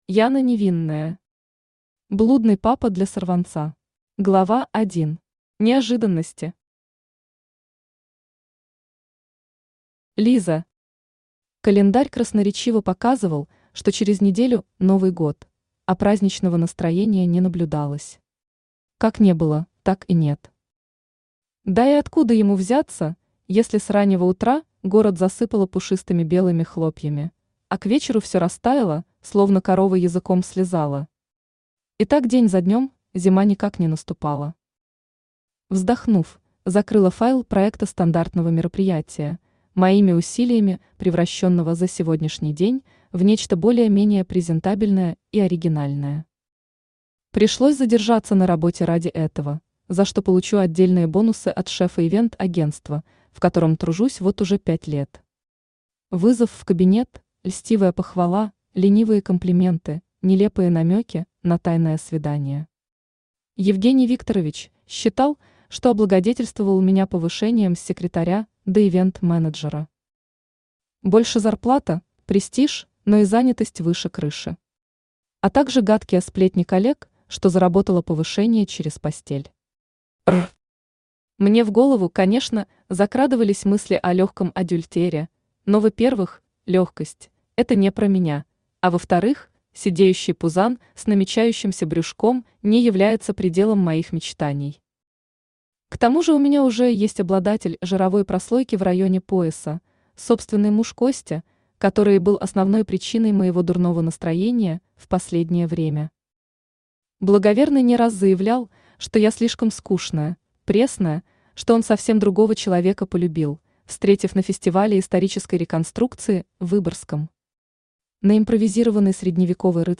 Аудиокнига Блудный папа для сорванца | Библиотека аудиокниг
Aудиокнига Блудный папа для сорванца Автор Яна Невинная Читает аудиокнигу Авточтец ЛитРес.